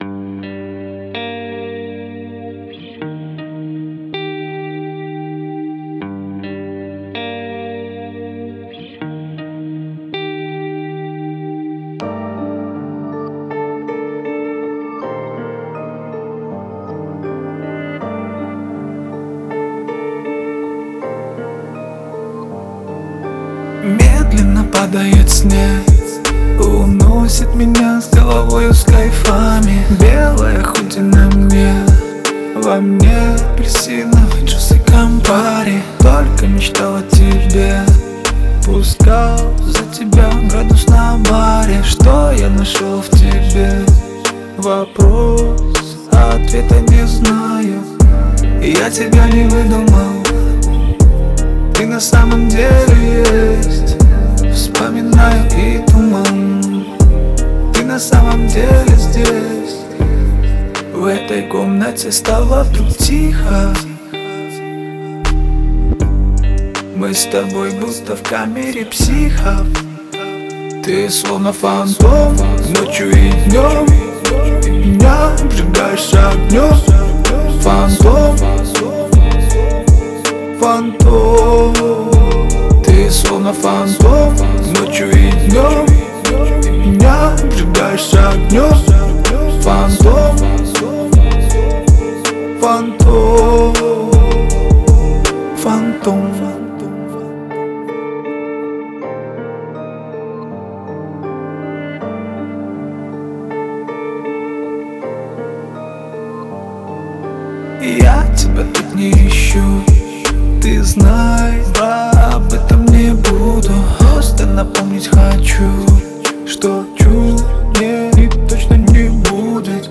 отличается плавными битами и запоминающейся мелодией